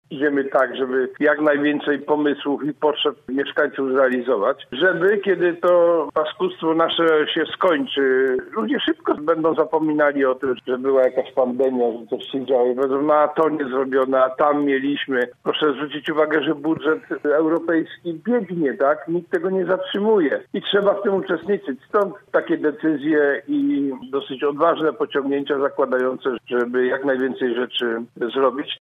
Andrzej Bocheński był gościem Rozmowy po 9.